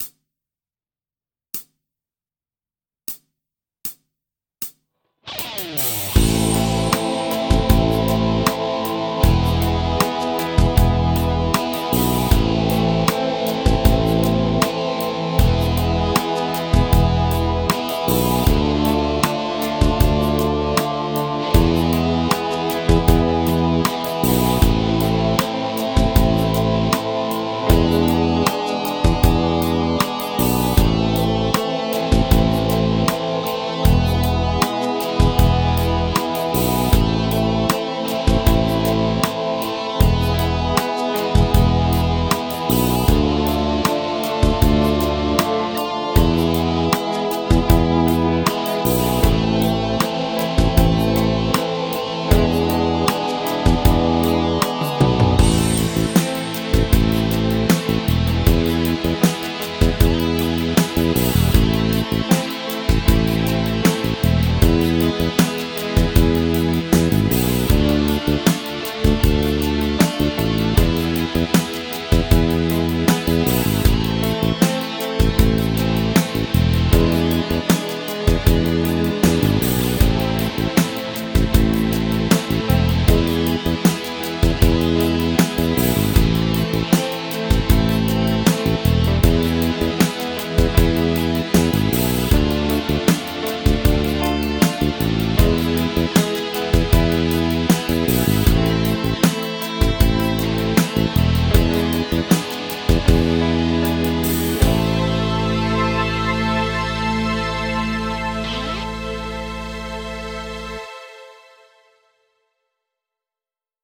メロディック・マイナー・スケール ギタースケールハンドブック -島村楽器